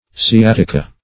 Sciatica \Sci*at"i*ca\, n. [NL.] (Med.)